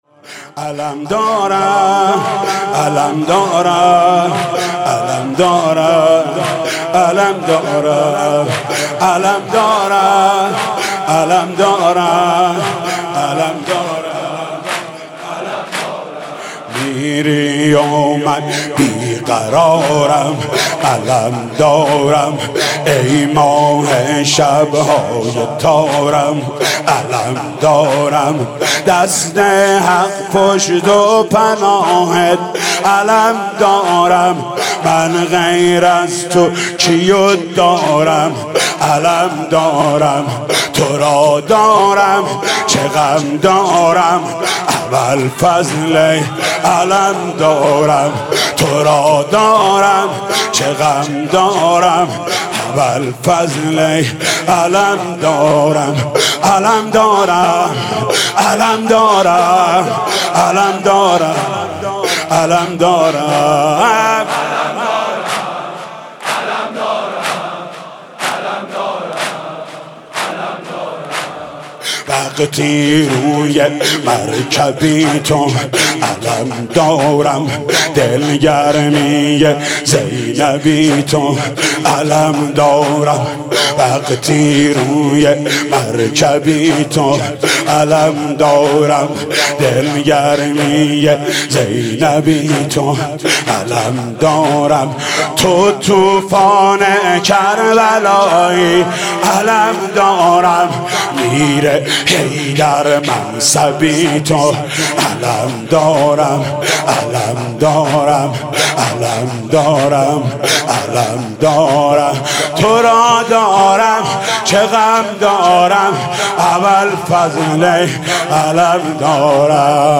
مناسبت : تاسوعای حسینی
مداح : محمود کریمی